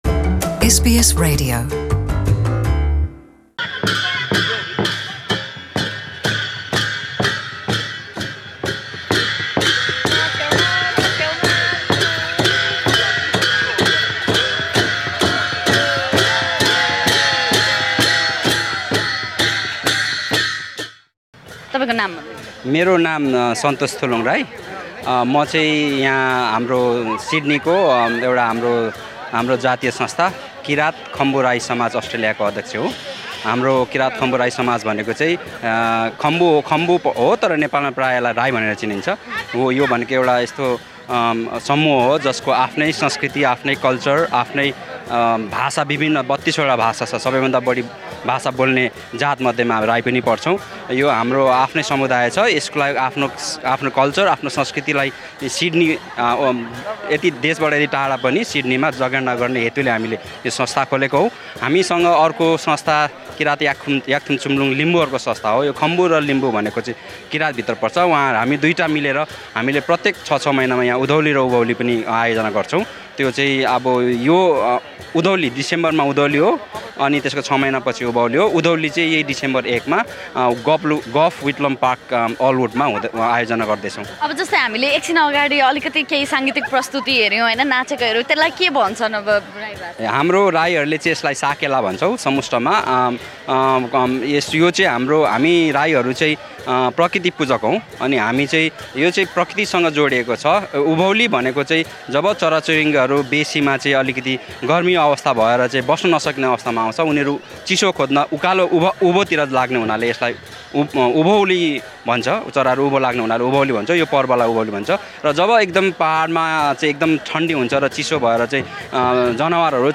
performing traditional Sakela dance moves in Sydney during Nepal Festival